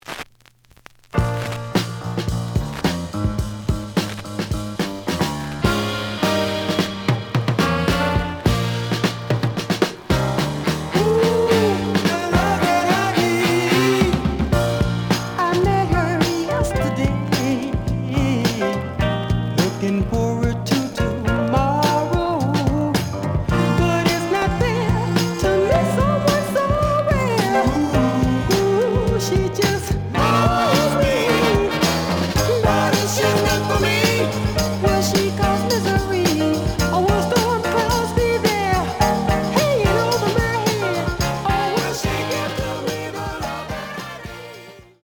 The audio sample is recorded from the actual item.
●Genre: Soul, 60's Soul
Some noise on beginning of B side.